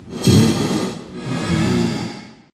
breathe4.ogg